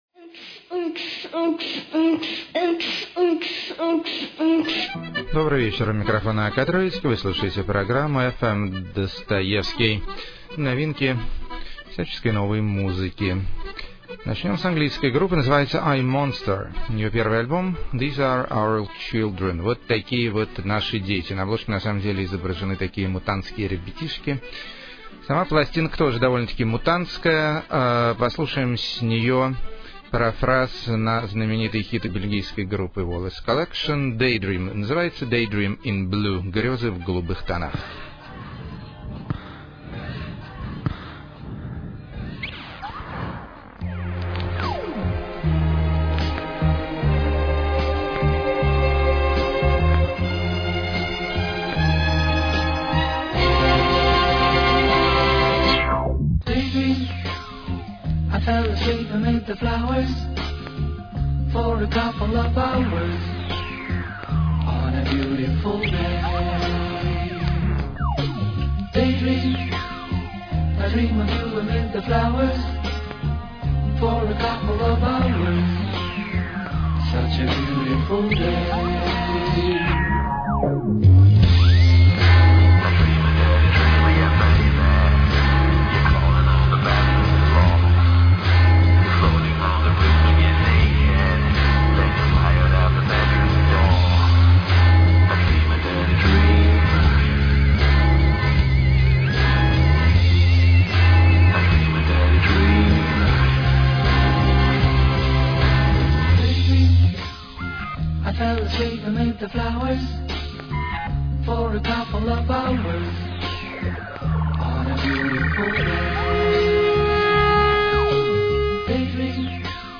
Интеллектуальные Электро-песенки.
Сюрной Вокальный Пост-рок.
Зажигательные Трансильванские Напевы.
Фантастическая Гитара И Красивые Мелодии, Лемур-рок.
Барокко-поп Из Гатчины.
Сентиментальная Электроника.